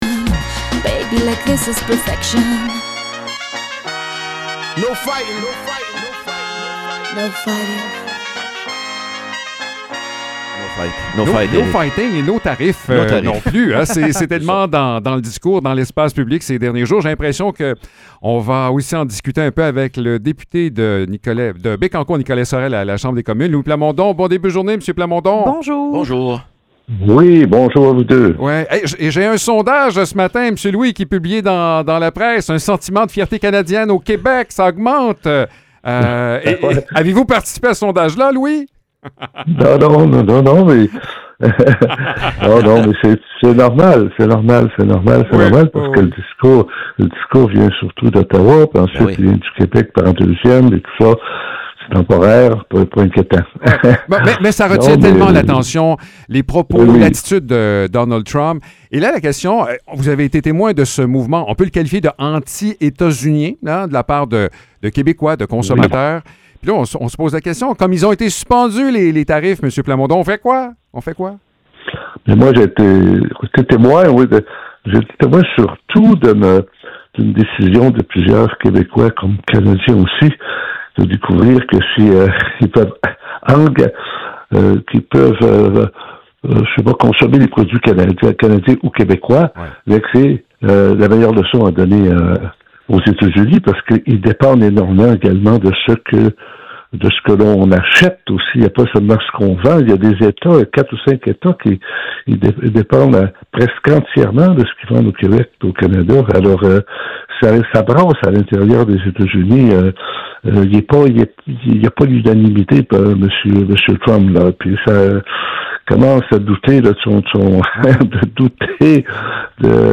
Échange avec Louis Plamondon